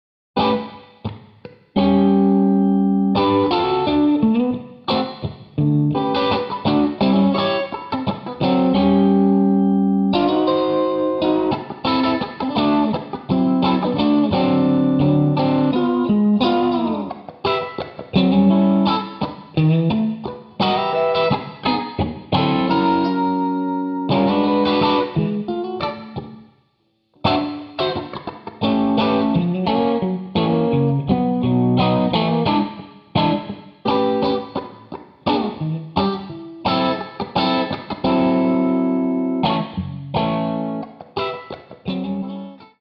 RhythmusGitarre 1
Rhythm.Git.1.wav